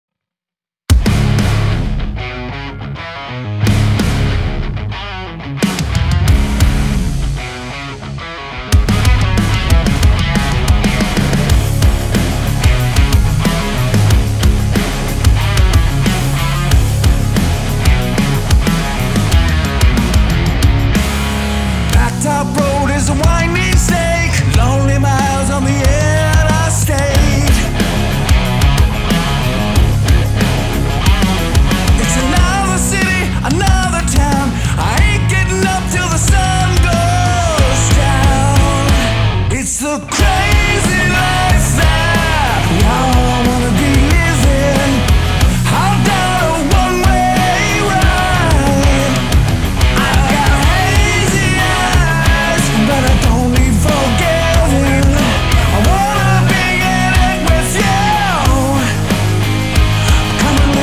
lead vocals
drums
bass
keyboards